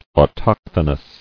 [au·toch·tho·nous]